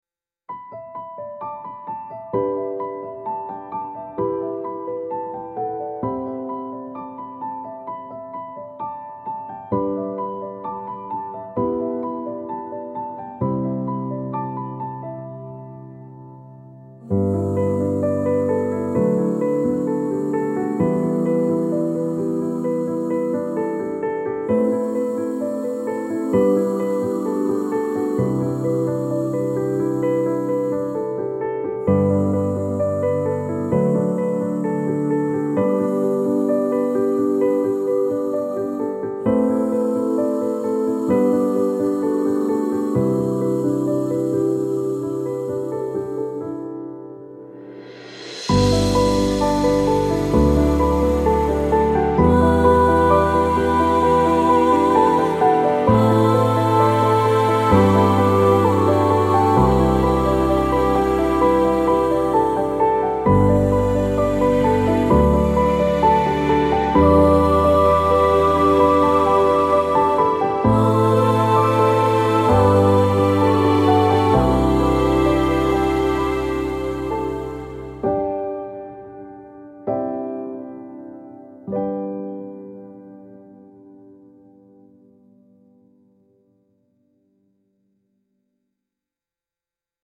gospel-inspired piano and soft choir with a message of hope and grace